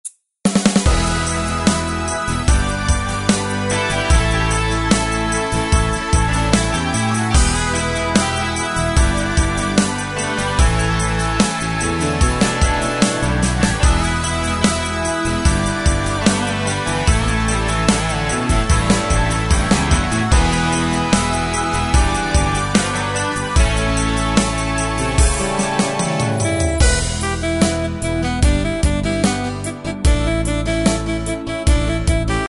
Tempo: 74 BPM.
MP3 with melody DEMO 30s (0.5 MB)zdarma